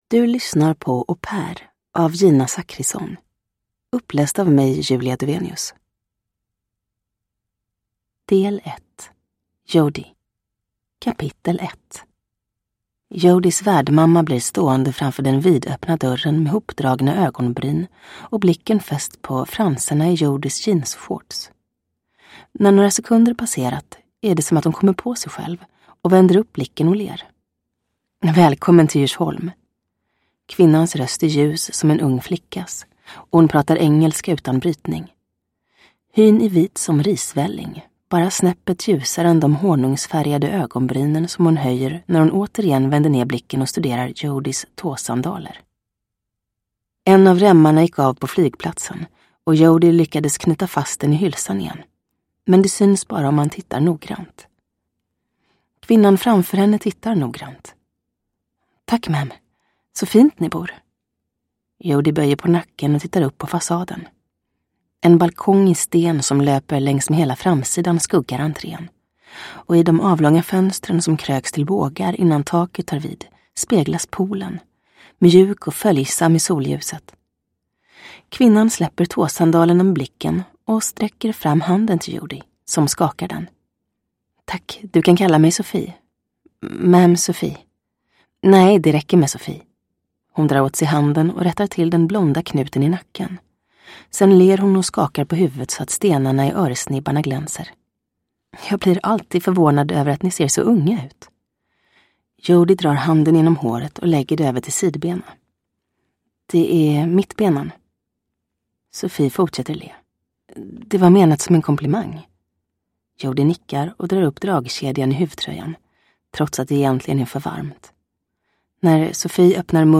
Au pair – Ljudbok
Uppläsare: Julia Dufvenius